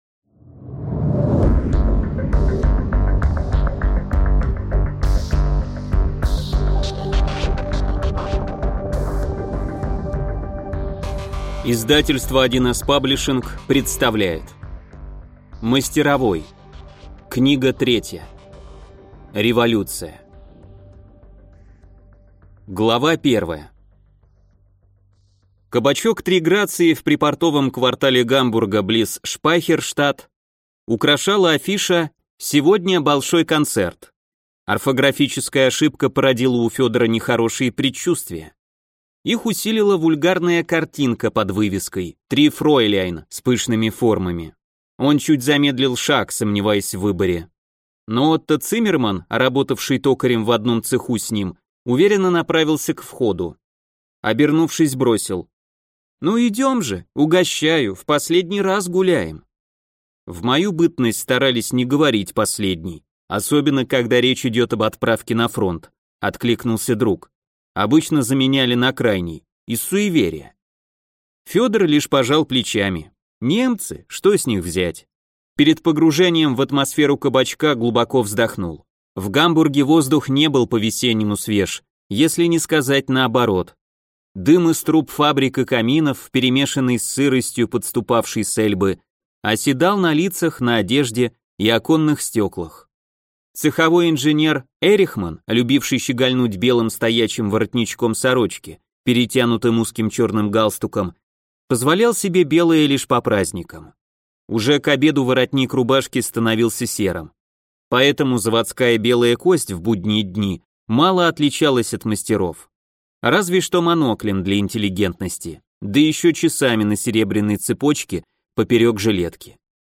Аудиокнига Мастеровой. Революция | Библиотека аудиокниг